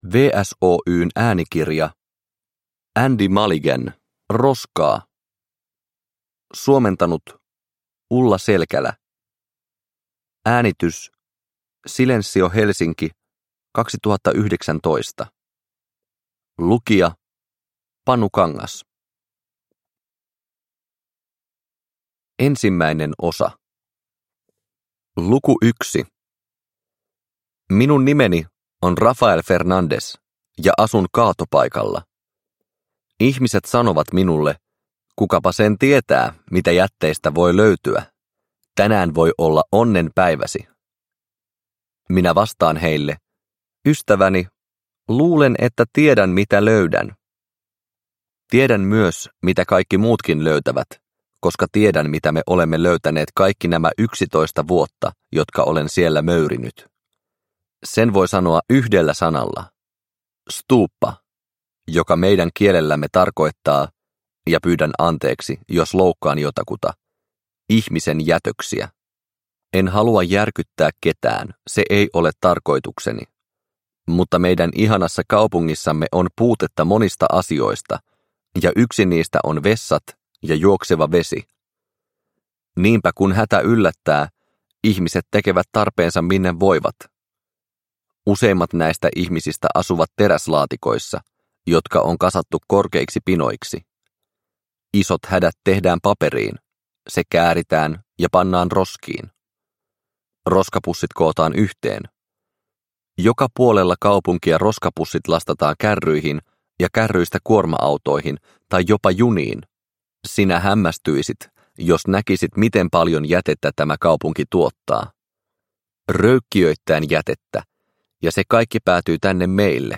Roskaa – Ljudbok – Laddas ner